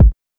Kick (All Mine).wav